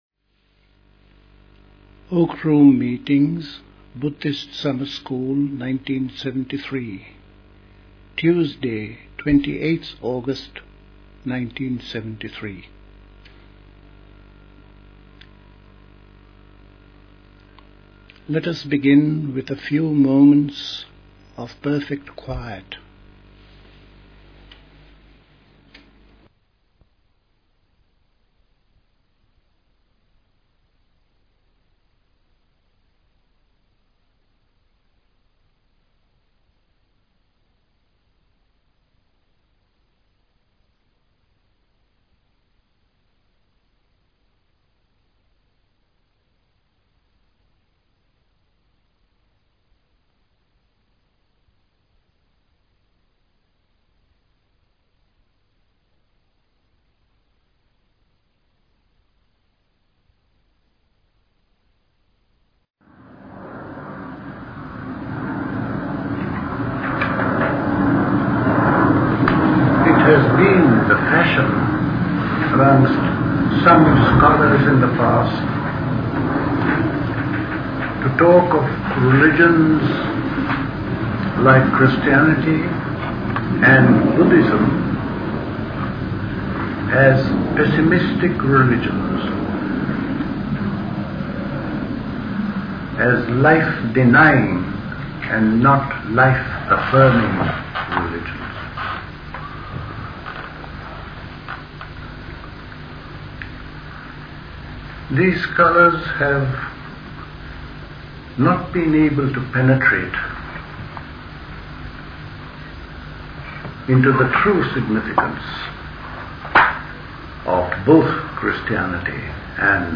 Recorded at the 1973 Buddhist Summer School.